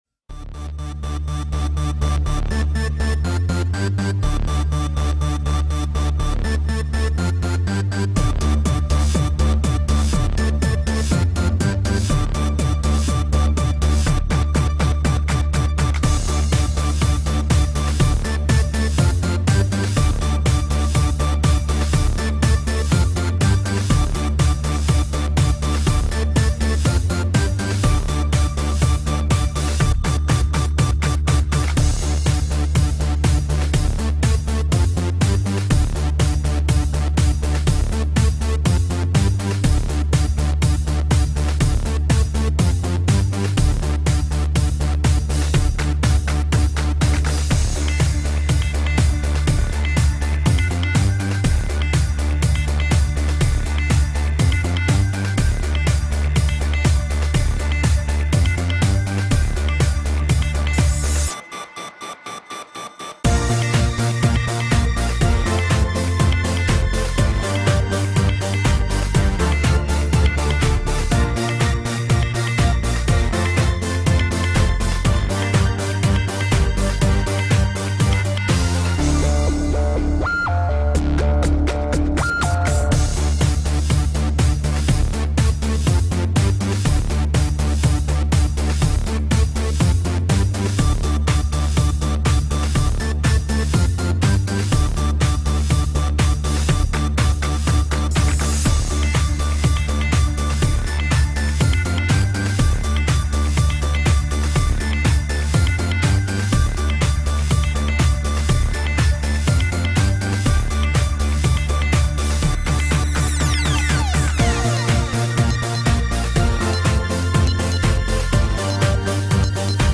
mix_6m21s-(clubmix).mp3